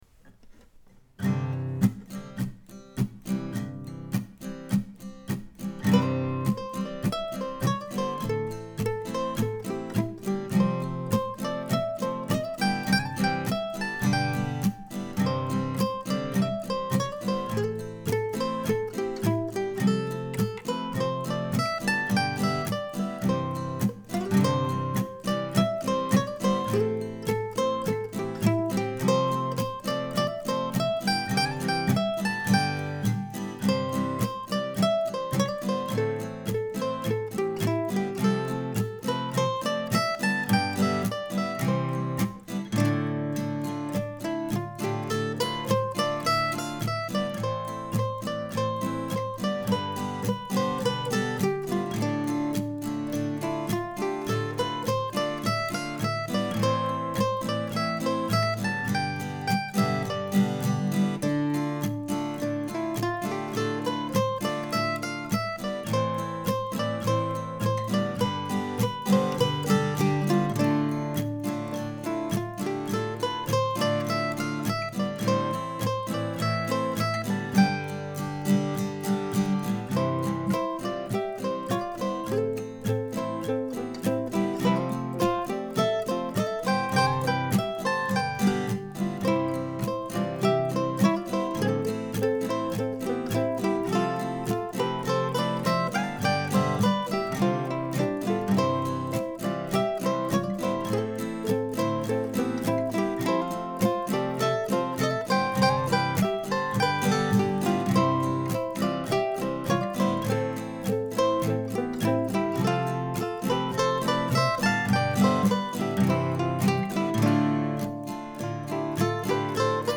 I finally settled on a slower, walking feel, perhaps influenced by watching the house pictured above move through our neighborhood at its measured pace on Wednesday morning.